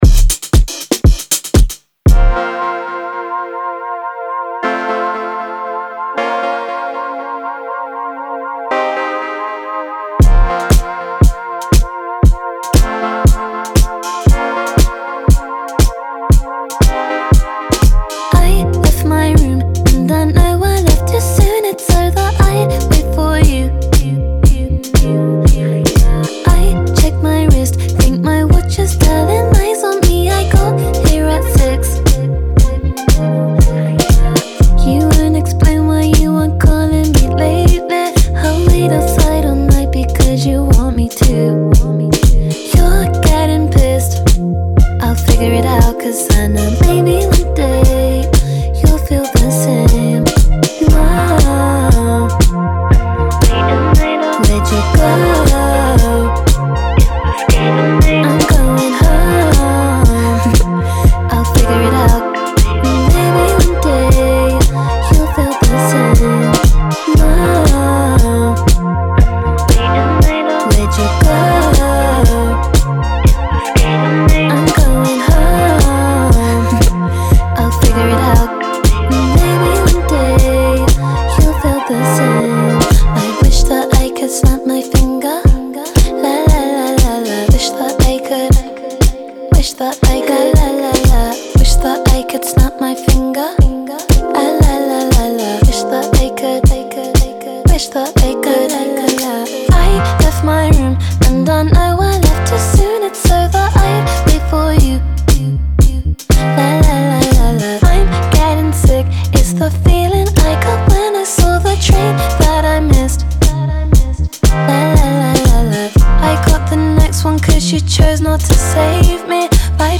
BPM118-118
R&B song for StepMania, ITGmania, Project Outfox